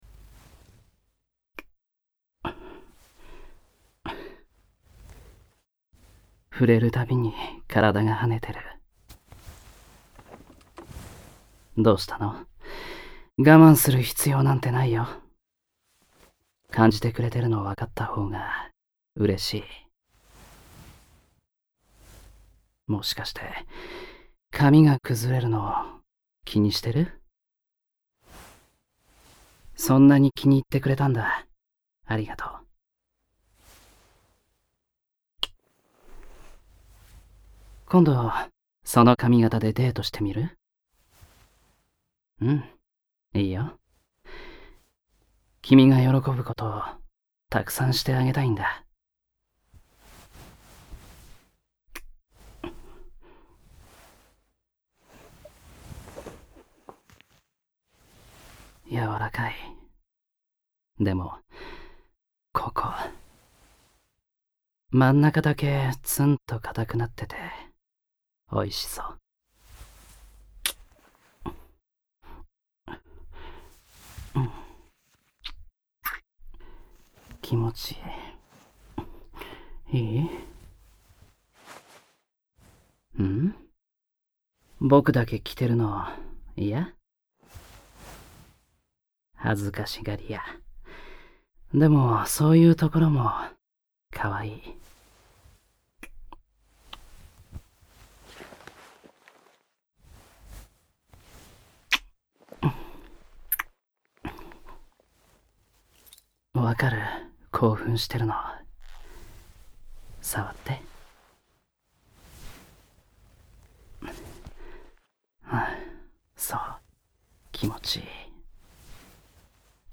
●18歳以上推奨　●全編ダミーヘッドマイクにて収録　※視聴サンプルは製品とは内容、仕様が異なる場合がございます。
ドラマCDを聴くならポケットドラマCD R（ポケドラR）